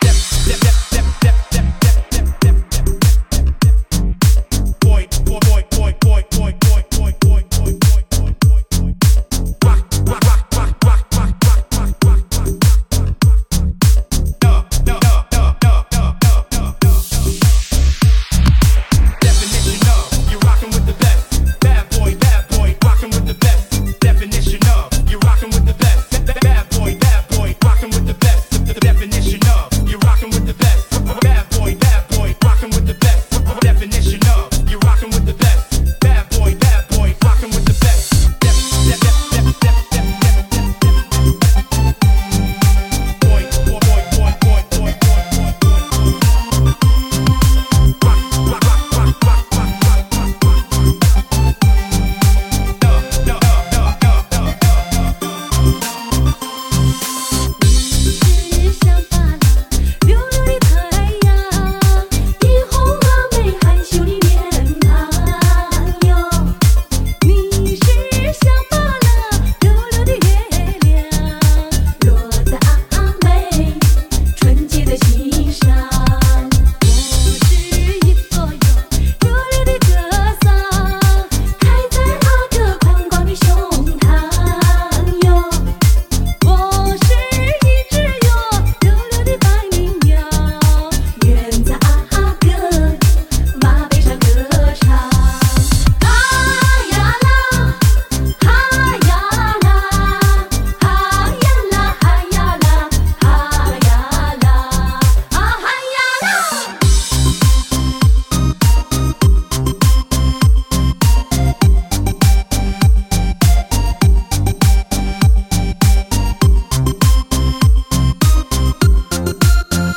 有史以来第一张发烧舞曲